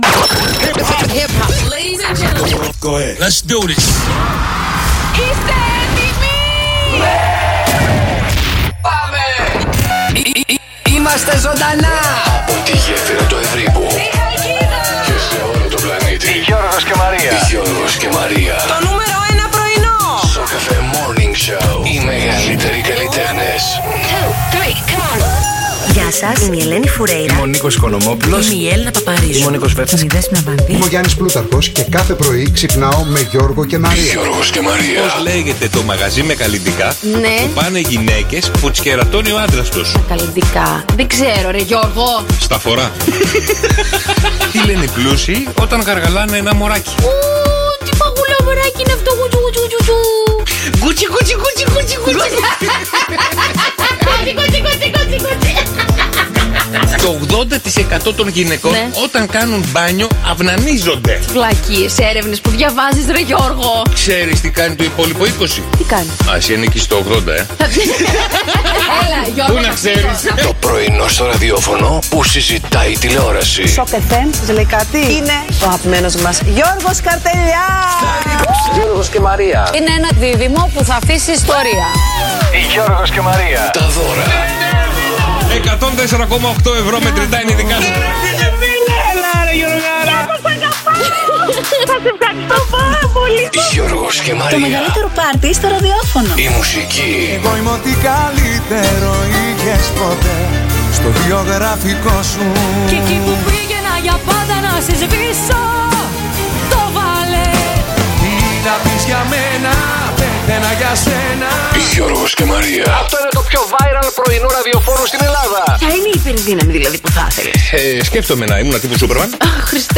Οι ακροατές μας τραγουδούν επιτυχίες